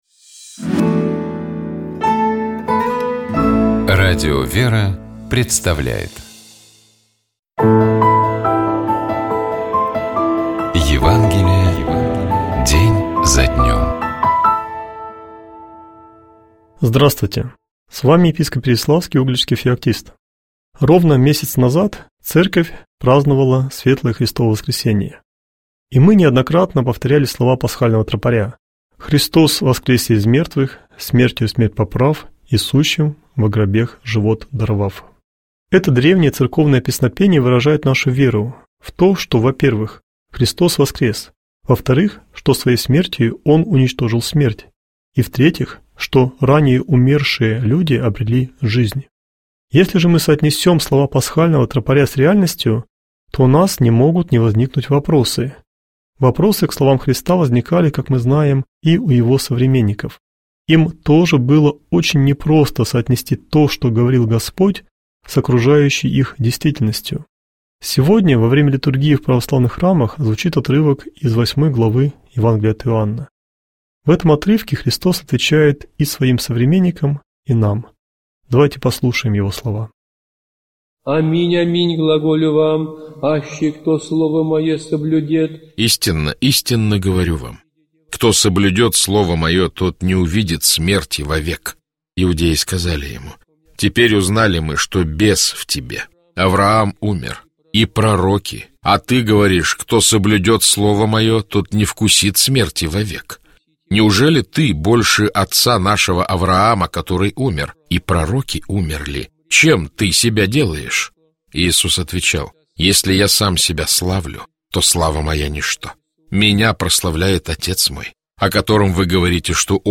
епископ Феоктист Игумнов Читает и комментирует епископ Переславский и Угличский Феоктист